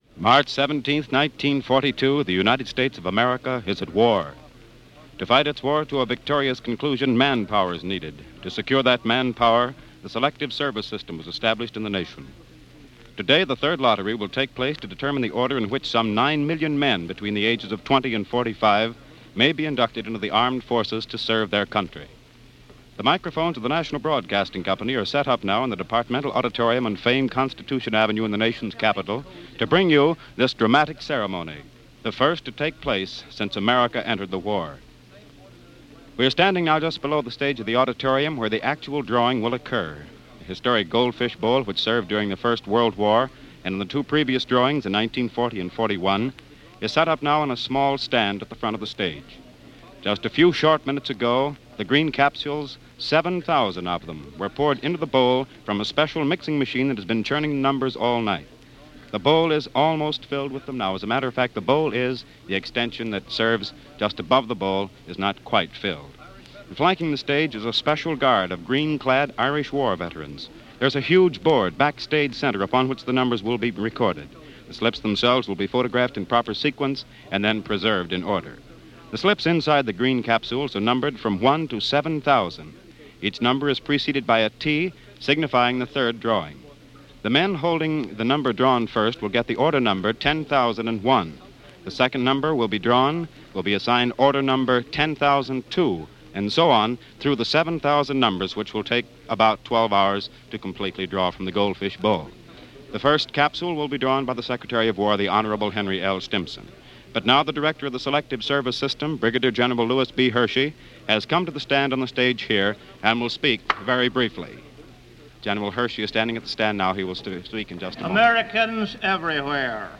NBC Radio
On hand were several dignitaries, each took turns picking numbers out of the bowl until all were taken out.